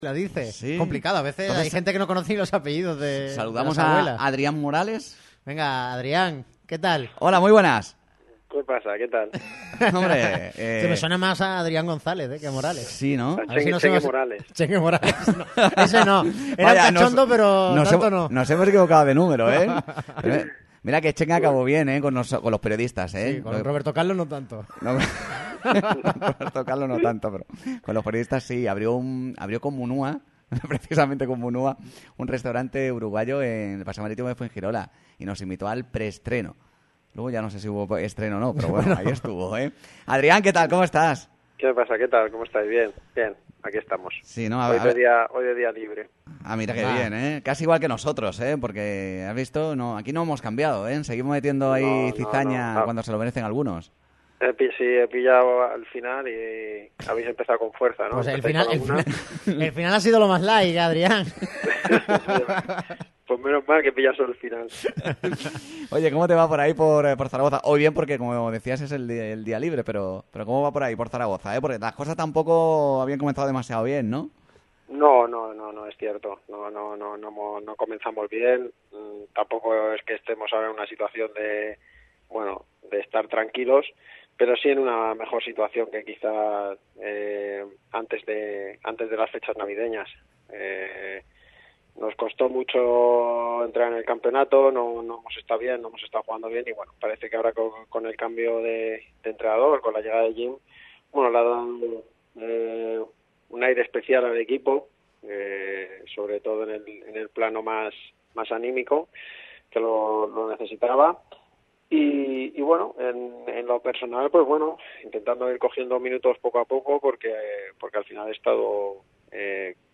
El excapitán boquerón habló para los micrófonos de Radio MARCA Málaga, donde recordó sus viejas raíces y trató su situación personal en el conjunto blanquillo.